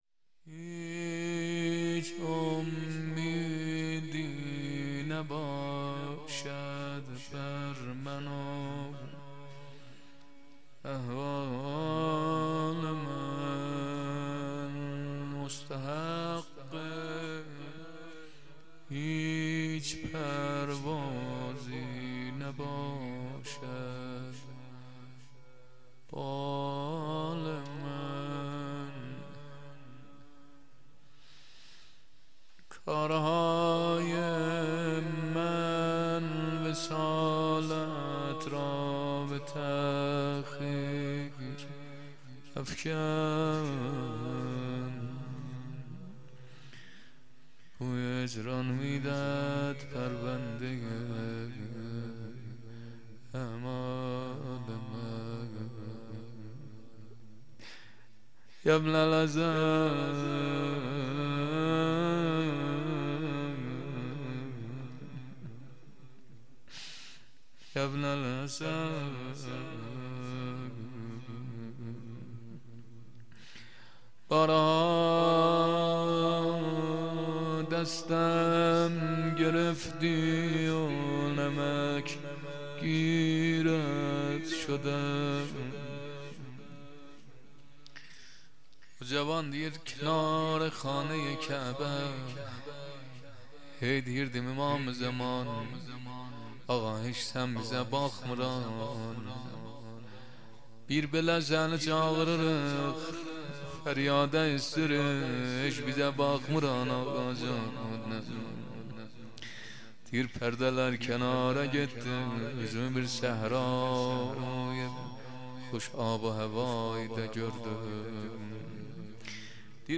مراسم هفتگی | 8 آذر ماه 1400